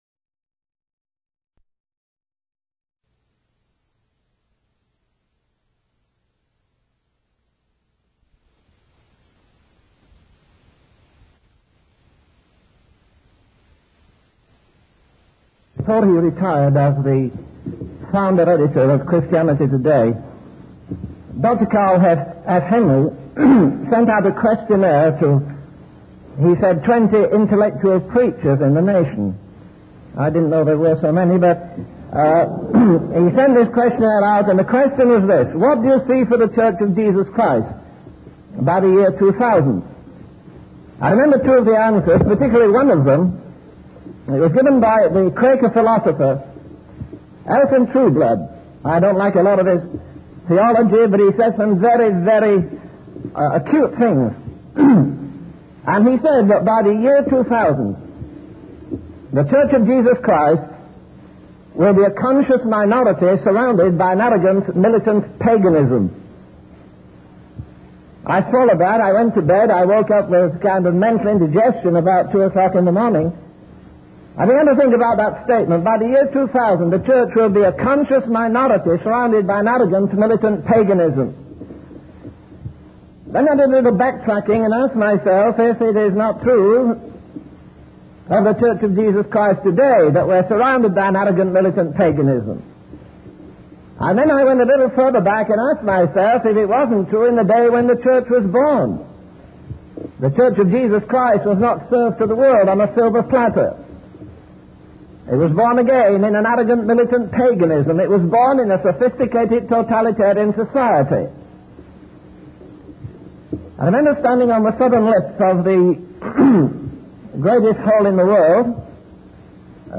Wise Desire Ministries helps convey various Christian videos and audio sermons.